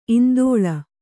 ♪ indōḷa